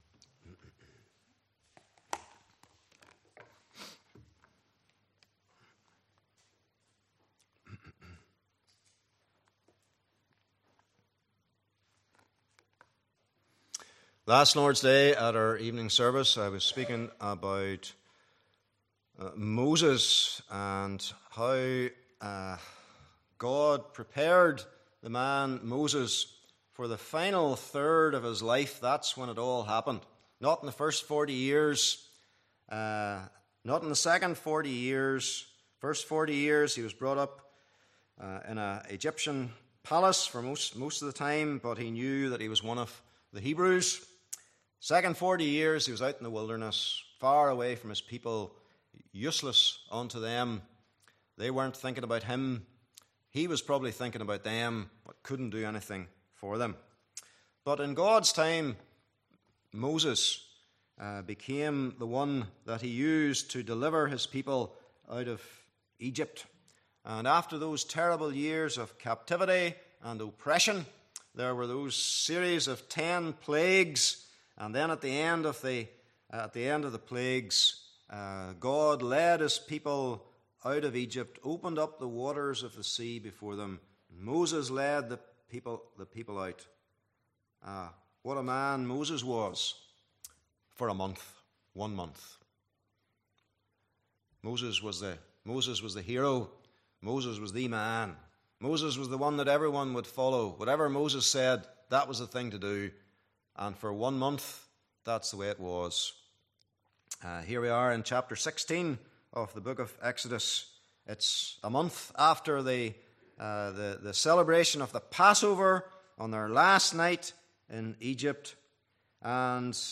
Passage: Exodus 16:1-36 Service Type: Evening Service